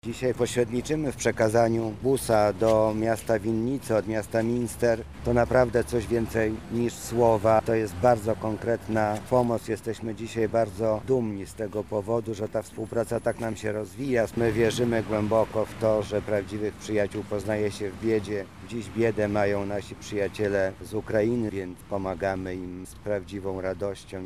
Mariusz Banach – dodaje Mariusz Banach, Zastępca Prezydenta Miasta Lublin ds. oświaty i wychowania.